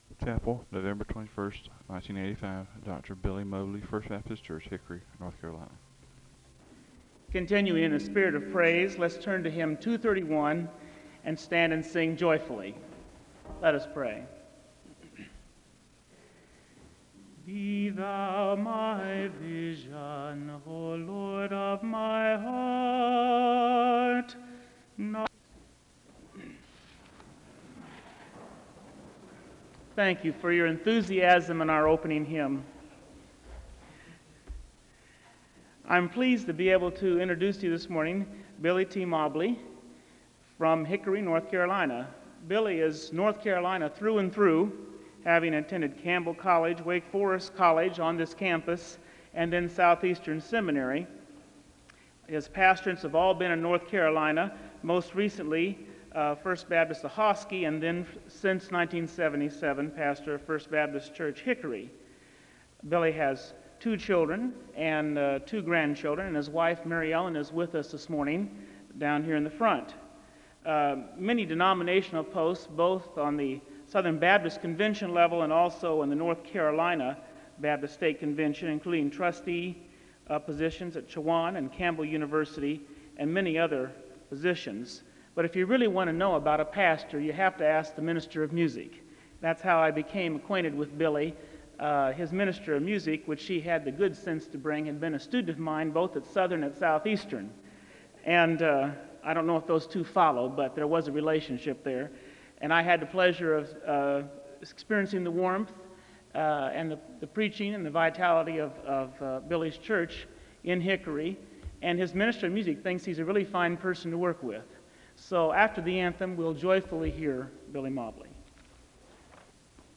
He encourages the congregation to read with him from Jeremiah 29:10-11 (3:48-4:40).
SEBTS Chapel and Special Event Recordings SEBTS Chapel and Special Event Recordings